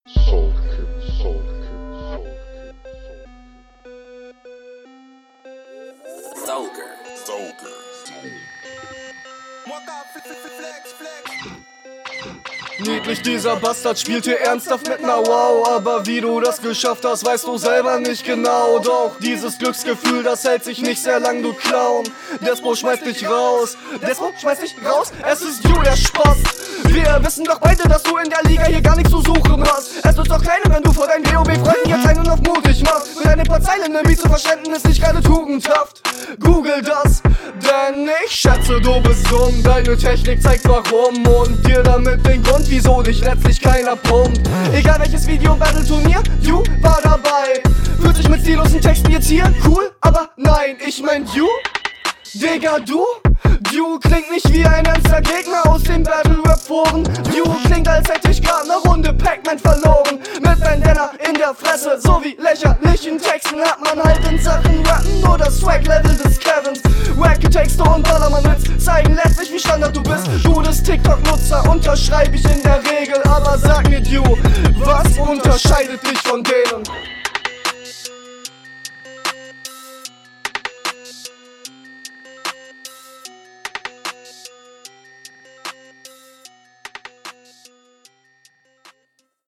wilder beat, kranke doubles. flowlich und technisch gut auf den beat maßgeschneidert. auch die angetrappten …